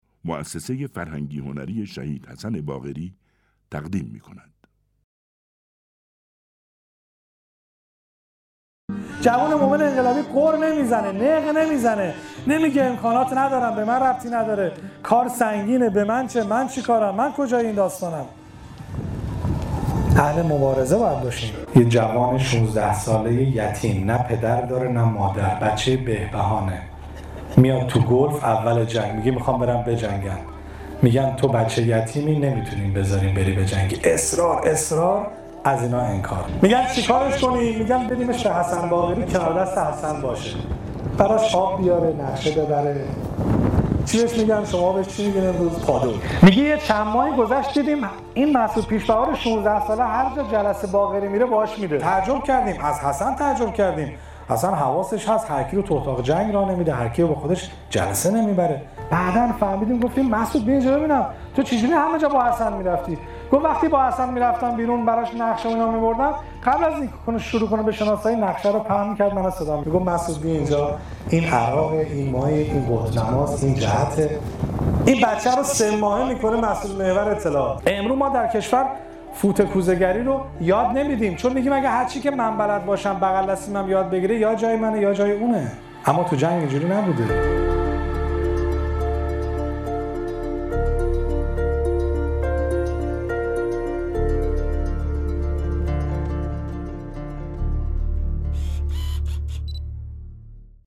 روایتگری در یادمان حسن باقری از جوان 16 ساله ای که حسن باقری 3 ماهه او را مسئول محور اطلاعات کرد.